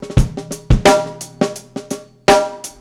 Badurim 1 86bpm.wav